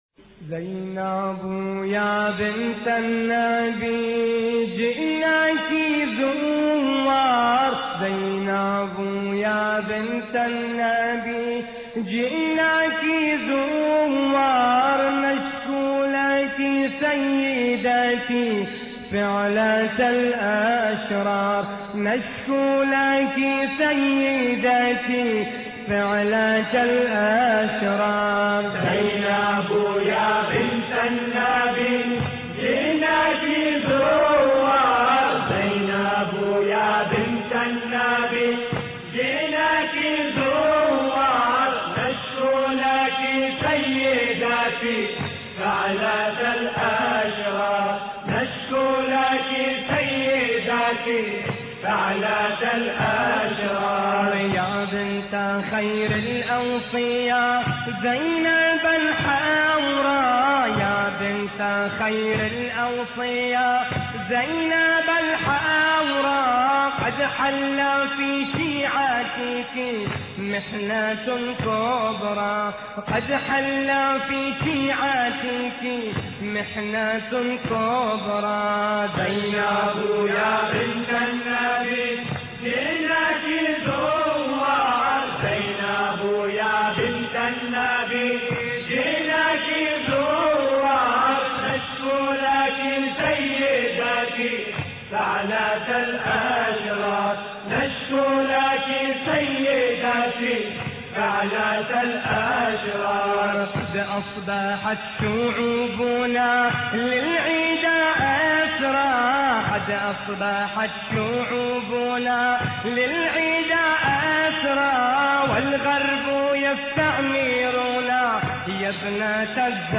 مراثي زينب الكبرى (س)